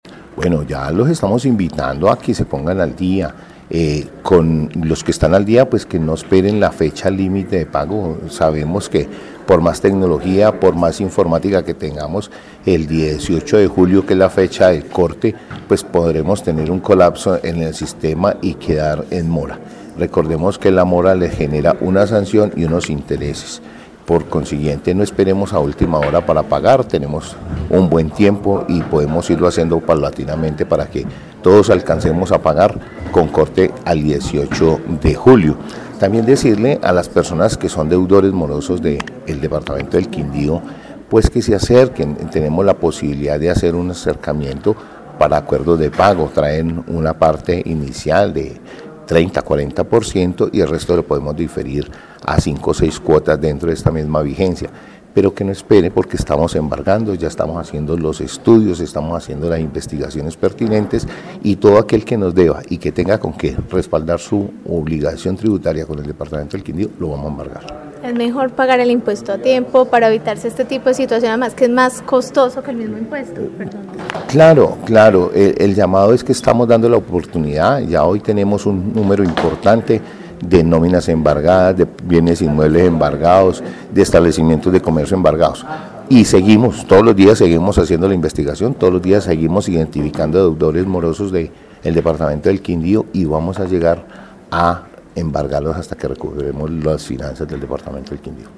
Secretario de Hacienda del Quindío